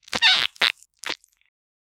wet-squelch-4tdy6wkh.wav